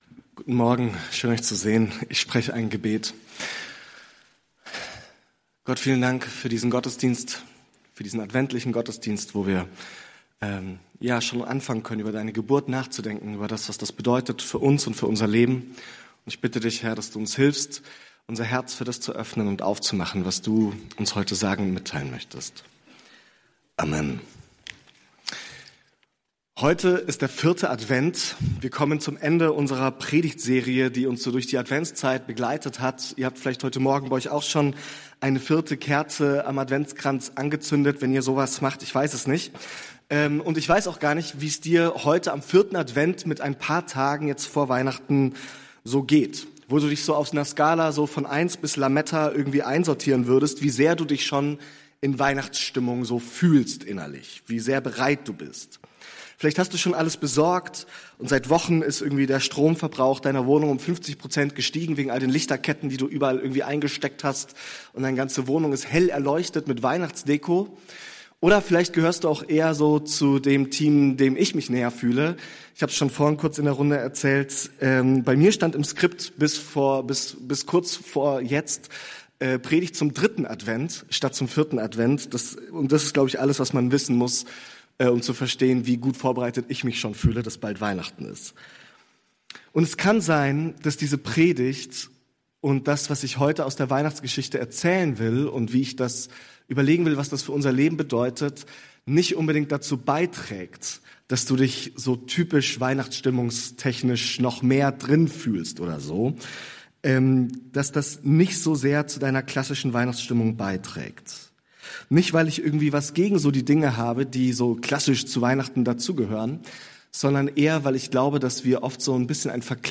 Was es für Weihnachten (nicht) braucht ~ Berlinprojekt Predigten Podcast